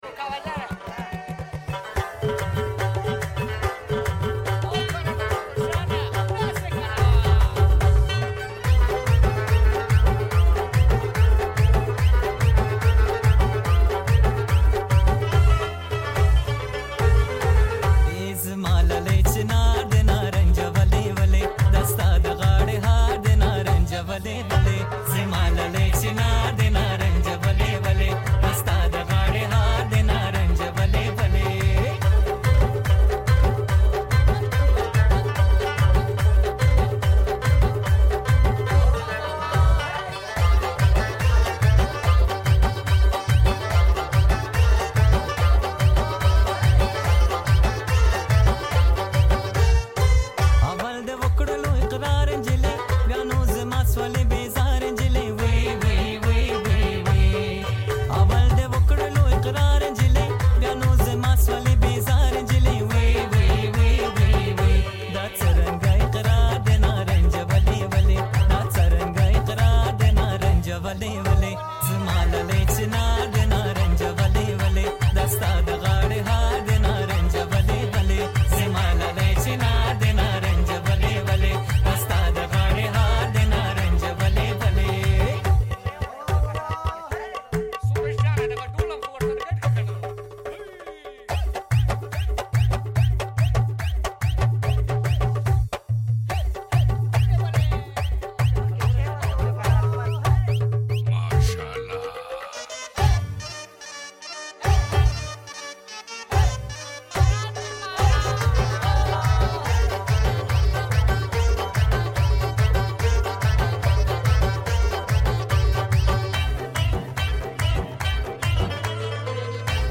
Pashto trending song